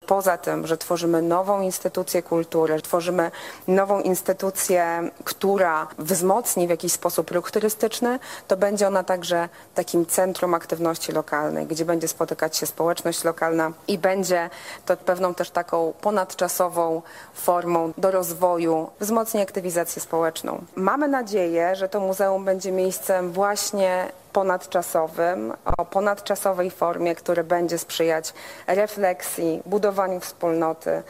Wiceminister podkreślała, że będzie to miejsce ponadczasowe, łączące pokolenia: